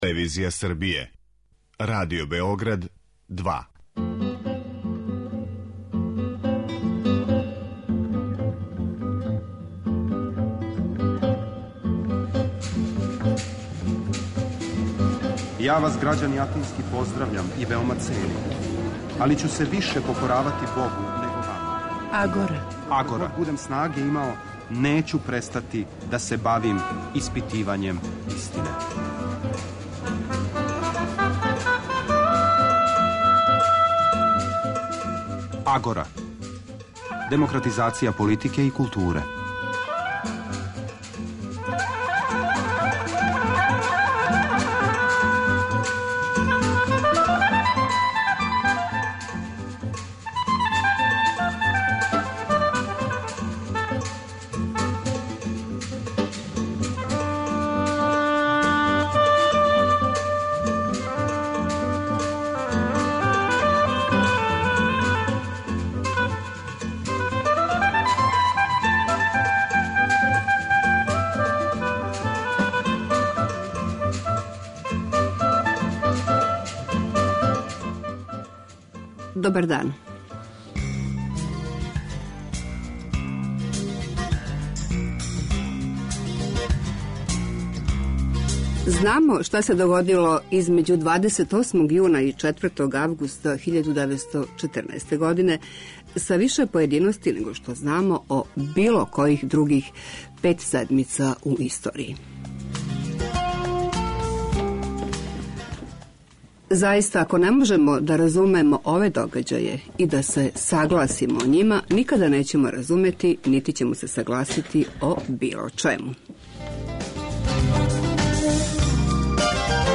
Репризирамо - занимљиве теме и саговорници у радио-магазину